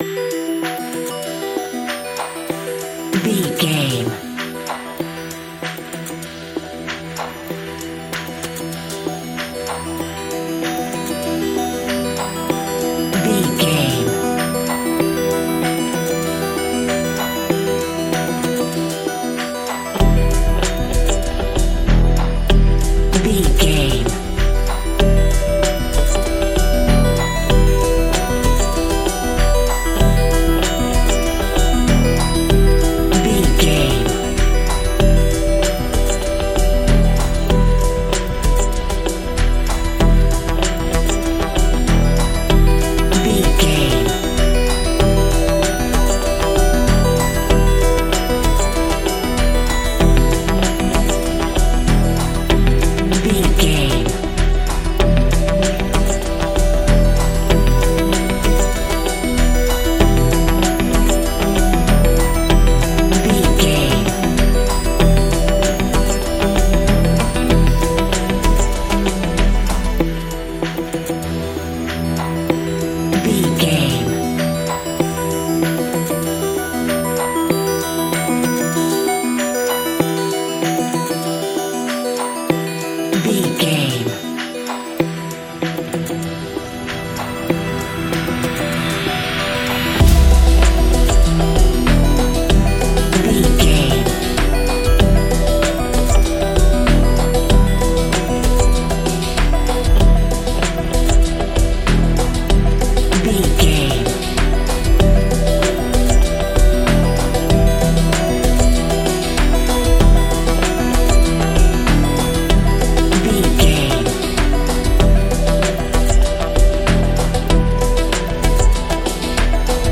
Ionian/Major
E♭
electronic
techno
trance
synths
synthwave